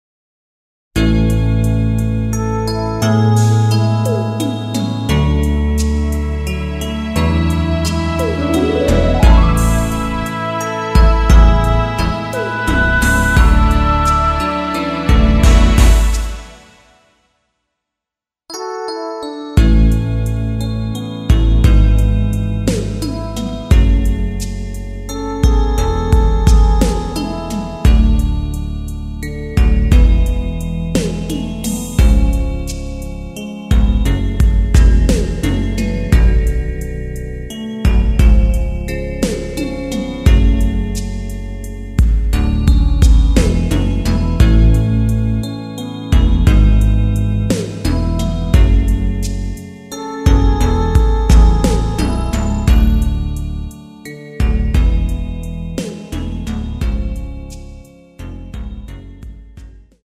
노래방에서 음정올림 내림 누른 숫자와 같습니다.
음정은 반음정씩 변하게 되며 노래방도 마찬가지로 반음정씩 변하게 됩니다.
앞부분30초, 뒷부분30초씩 편집해서 올려 드리고 있습니다.
중간에 음이 끈어지고 다시 나오는 이유는